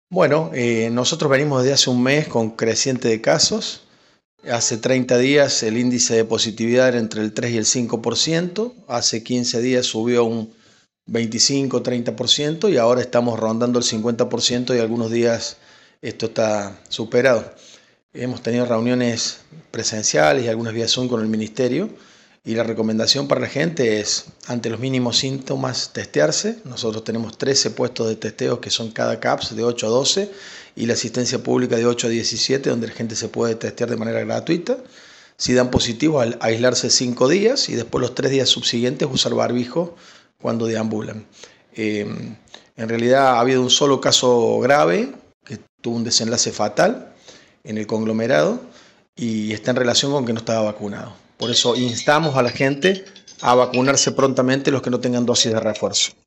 Pedro Trecco, titular de la cartera de salud, municipalidad de Villa María.